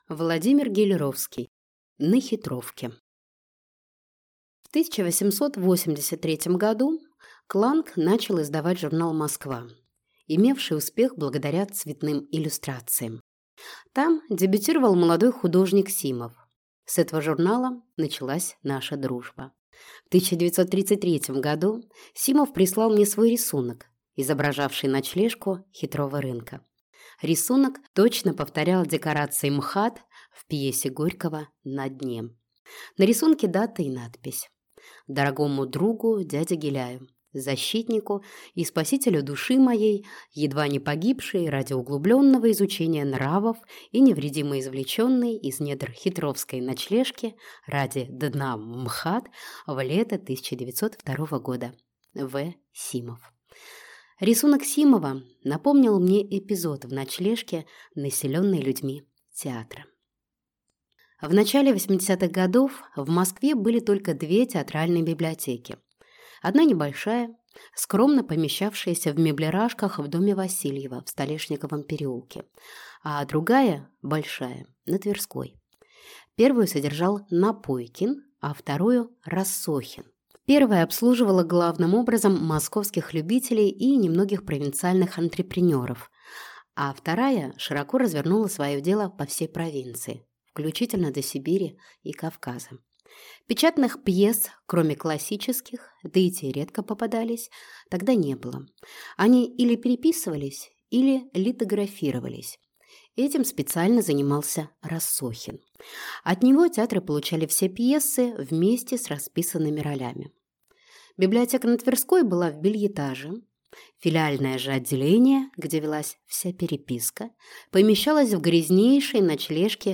Аудиокнига На Хитровке | Библиотека аудиокниг